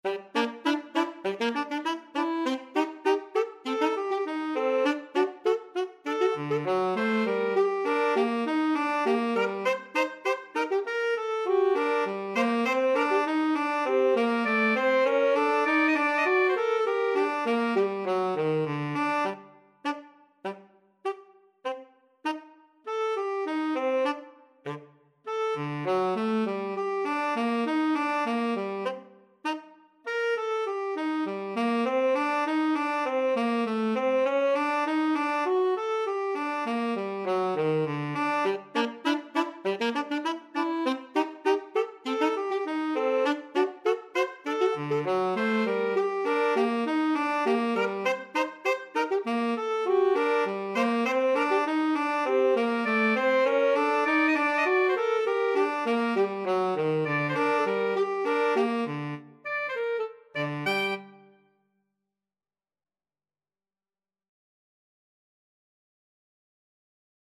4/4 (View more 4/4 Music)
Jazz (View more Jazz Alto-Tenor-Sax Duet Music)